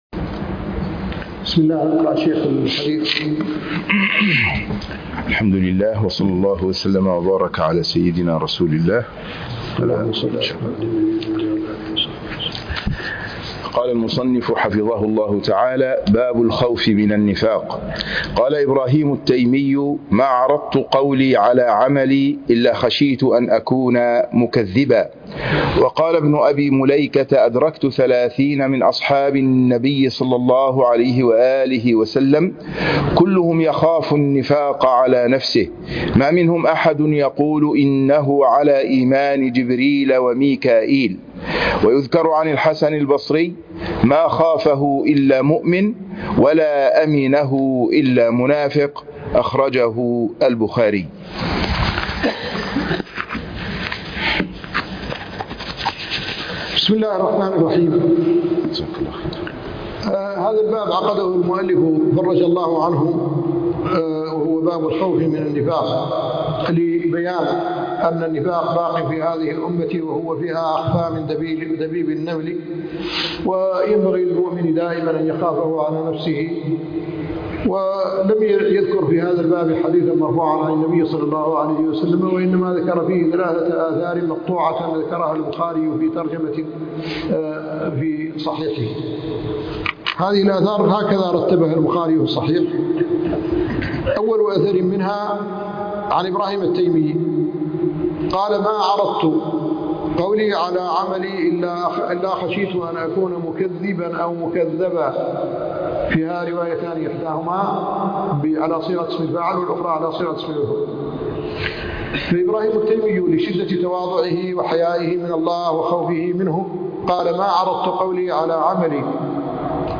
الدرس السادس والثلاثون من معالم السنة - الشيخ محمد الحسن ولد الددو الشنقيطي